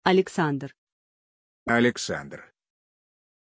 Aussprache von Alexandr
pronunciation-alexandr-ru.mp3